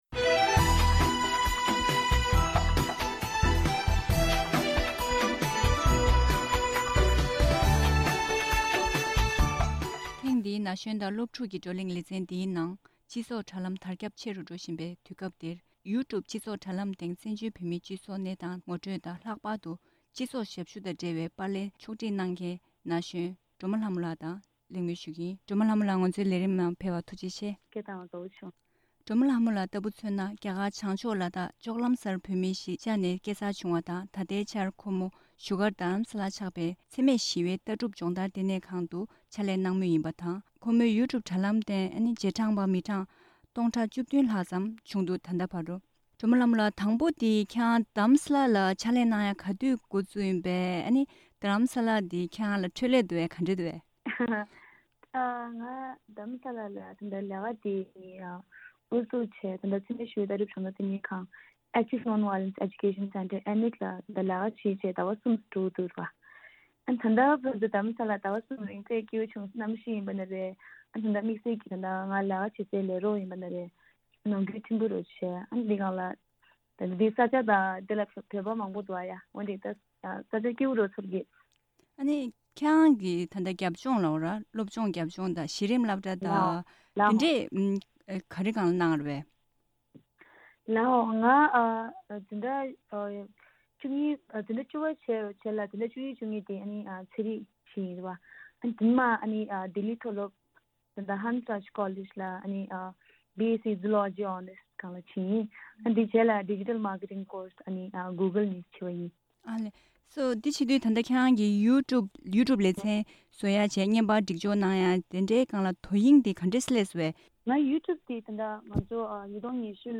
ཐེངས་འདིའི་ན་གཞོན་དང་སློབ་ཕྲུག་གི་བགྲོ་གླེང་ལེ་ཚན་ནང་།